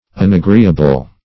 Search Result for " unagreeable" : The Collaborative International Dictionary of English v.0.48: Unagreeable \Un`a*gree"a*ble\, a. 1.